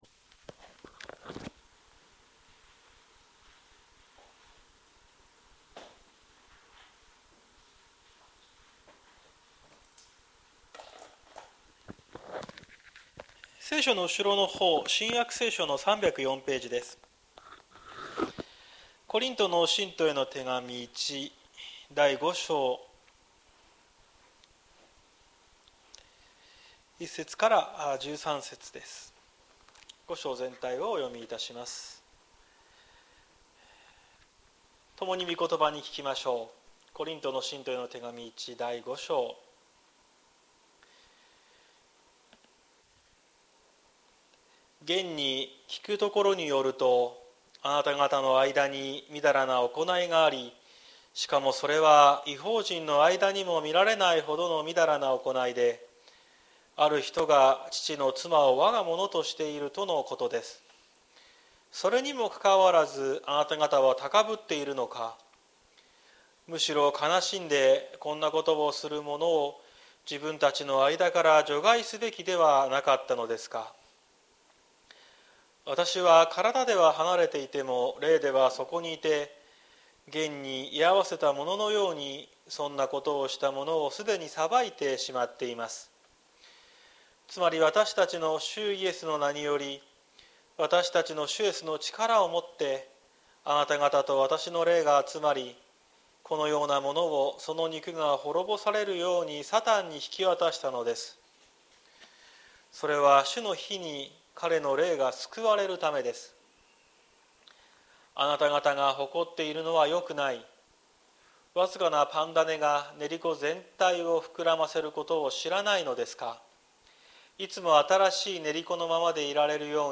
2024年11月03日朝の礼拝「神の愛とあるべき姿」綱島教会
綱島教会。説教アーカイブ。